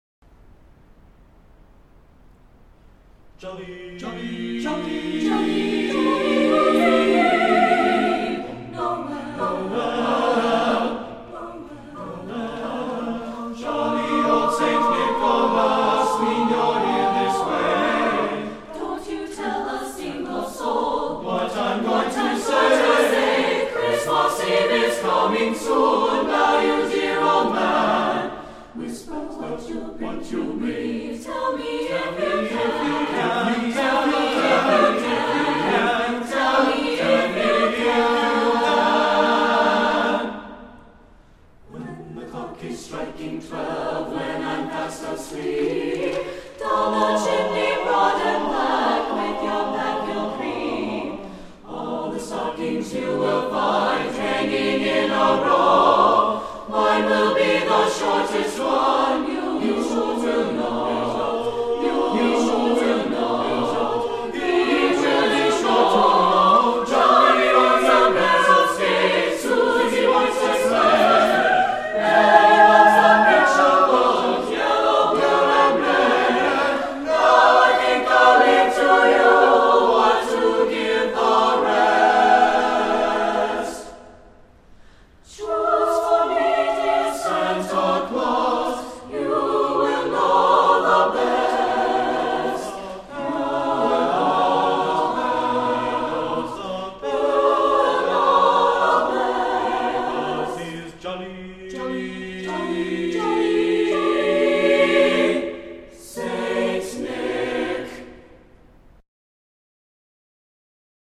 Voicing: SATBB divisi